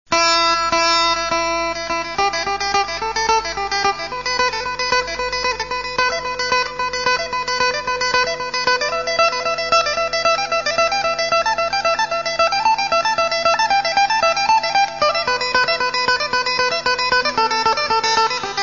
พิณ
เพื่อให้ท่านได้รับฟังความงามของลายพิณอีสาน จึงได้นำเอาตัวอย่างของลายพิณที่ดีดโดยศิลปินพื้นบ้านมาทำเป็นไฟล์ MP3 ให้ท่านดาวน์โหลดไปฟัง ต้องขออภัยในคุณภาพเสียงครับเพราะเป็นแค่ระบบโมโนเท่านั้นแต่ก็ชัดเจนพอควรครับบันทึกจากคาสเซ็ทธรรมดา ณ ลานบ้าน (ไม่มีห้องอัดครับ) เลือกเอาที่โหลดได้นะครับผมเก็บไว้ 2 แห่ง (A หรือ B)